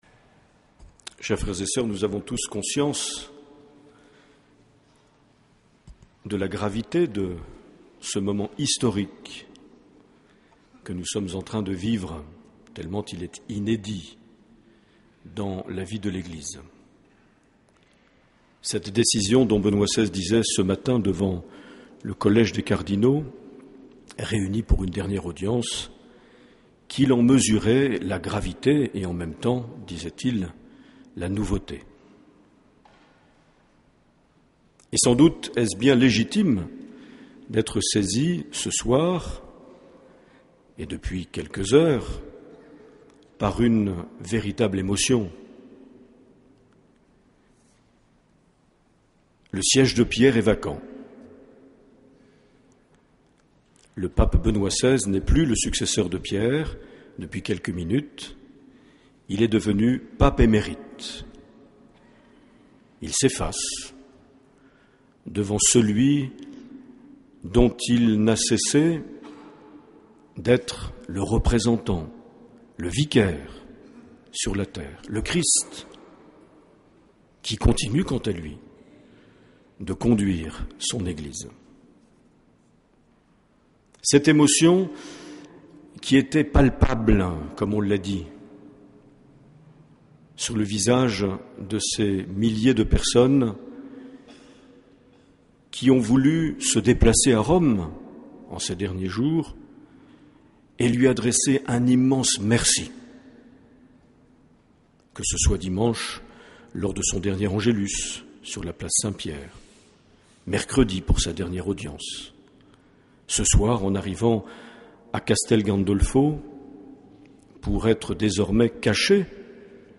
28 février 2013 - Cathédrale de Bayonne - Messe d’action de grâces pour le Pontificat de Benoît XVI
Accueil \ Emissions \ Vie de l’Eglise \ Evêque \ Les Homélies \ 28 février 2013 - Cathédrale de Bayonne - Messe d’action de grâces pour le (...)
Une émission présentée par Monseigneur Marc Aillet